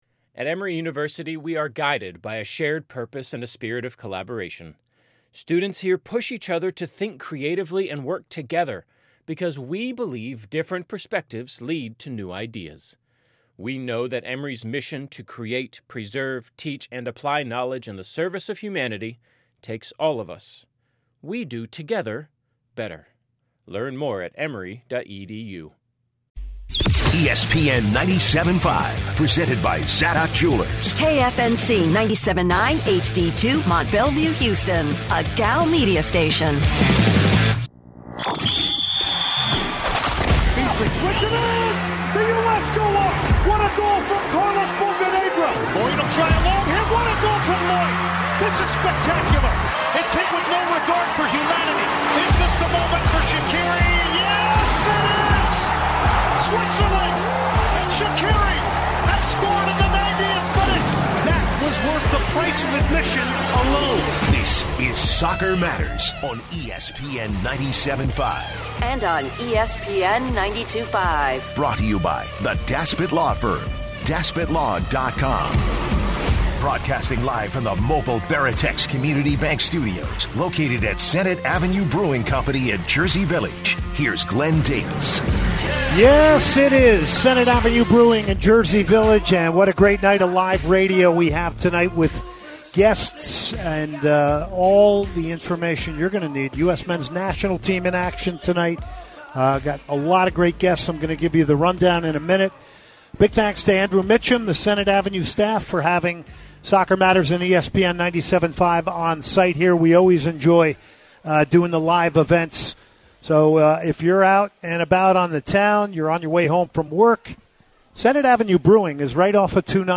Houston Dash goalkeeper Jane Campbell live and in the house on recent Dash success, influences, being a goalkeeper and more!